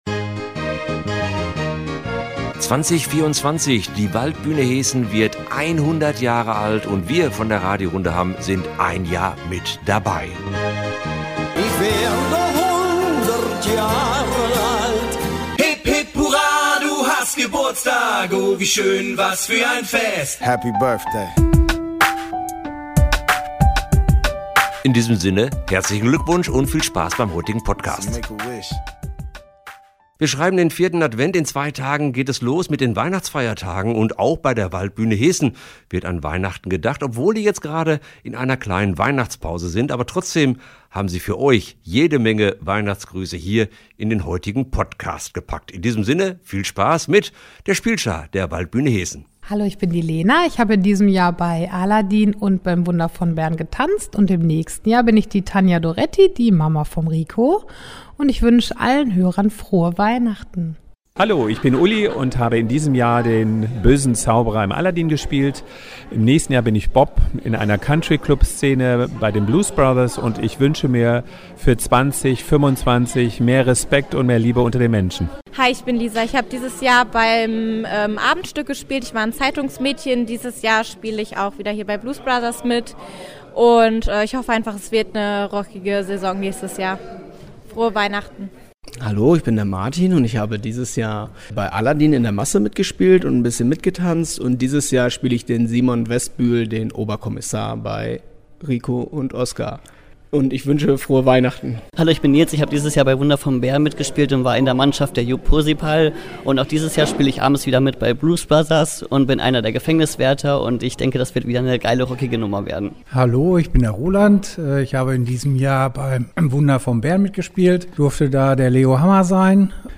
Nein, wir können nicht alle der Spielschar der Waldbühne Heessen zu Wort kommen lassen, aber stellvertretend haben wir unser Podcast-Mikro nach bzw. vor zwei der drei inzwischen laufenden Sprecherproben kreisen lassen.
Freut Euch auf ein Wiederhören einiger, die uns im abgelaufenen Jahr schon Rede und Antwort gestanden haben, aber auch über einige neue Stimmen.